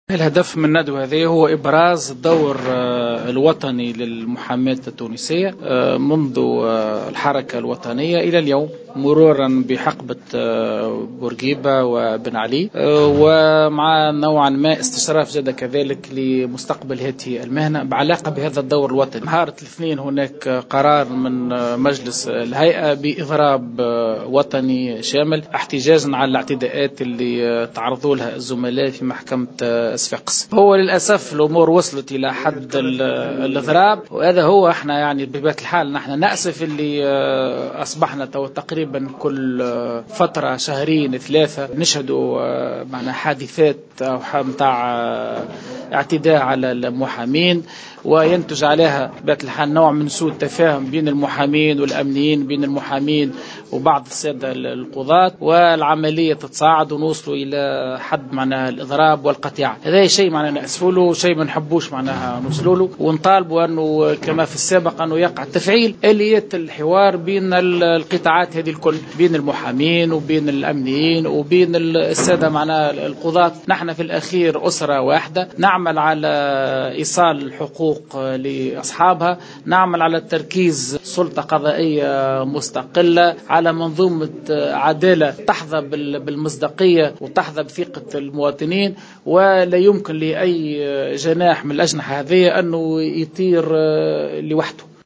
Selon Chaouki Tabib, président de la ligue tunisienne de citoyenneté, a exprimé au micro de Jawhara FM, son regret de la multiplication des grèves des avocats, appelant à l’activation des mécanismes de communication entre les avocats, les magistrats et les sécuritaires.